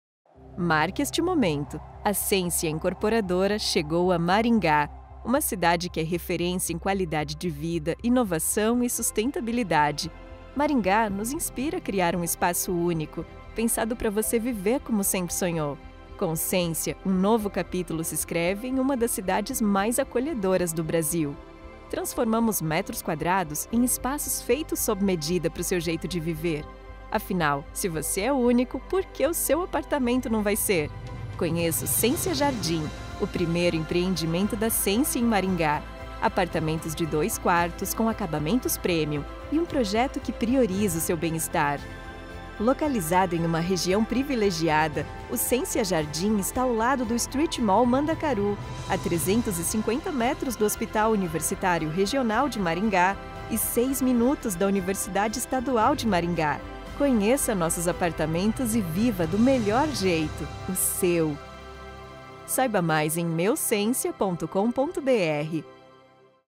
locutora brasileira, com voz jovem adulta, apta para locuções em diversos estilos, versatilidade e qualidade de entrega.
Sprechprobe: Industrie (Muttersprache):
I am always looking to deliver the best quality, without leaving the quality and punctuality of delivery, I can speak with a neutral accent and also a Gaucho accent from the south of Brazil.